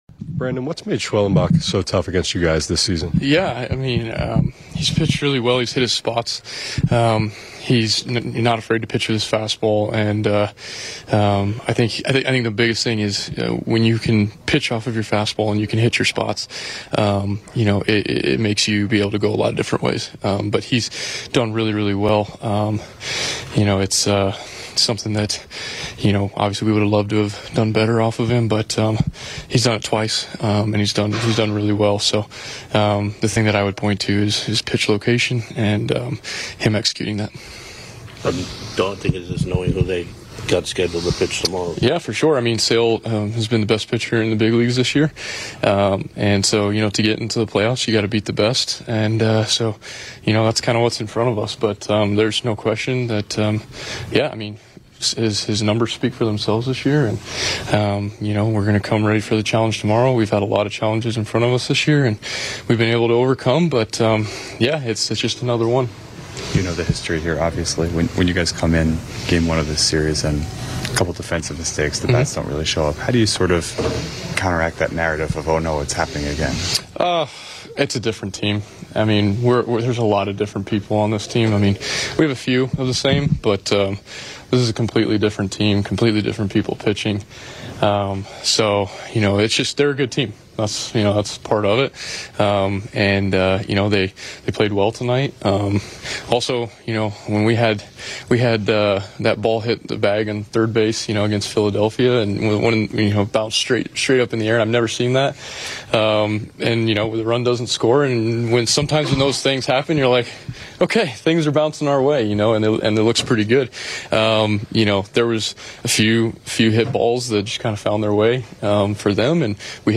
Mets outfielder Brandon Nimmo speaks to the media after Tuesday's loss in Atlanta.